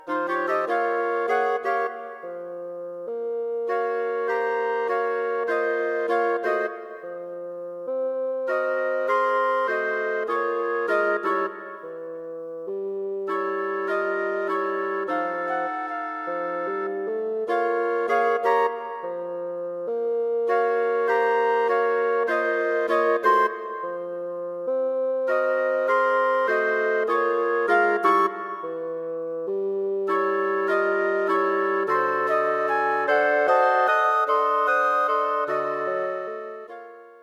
Wind Quartet for Concert performance